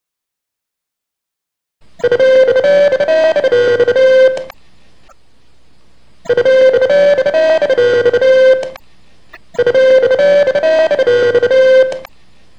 HOTLINE Ring Tone in MP3 https